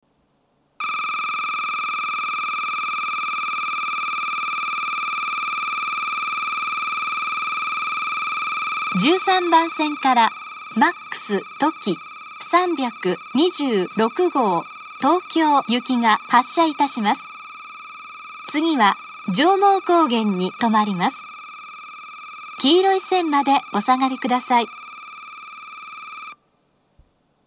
１３番線発車ベル Ｍａｘとき３２６号東京行の放送です。